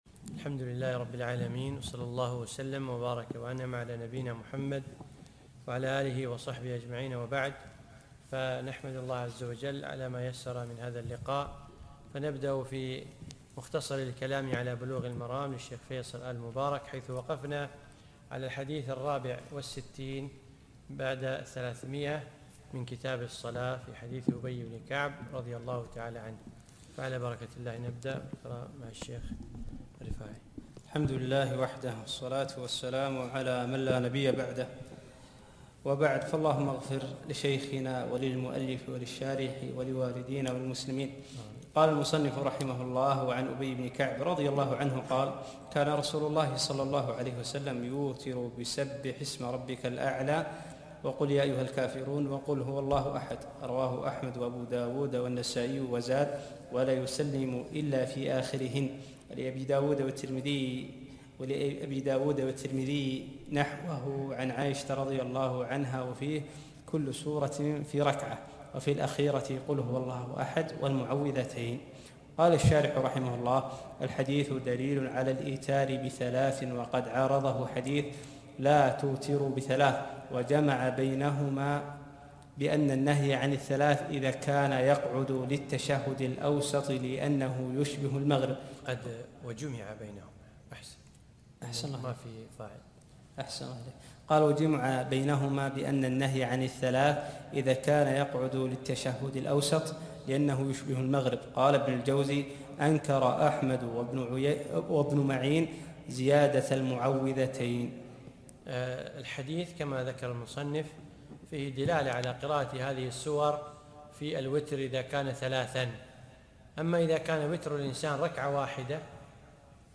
الدرس الخامس عشر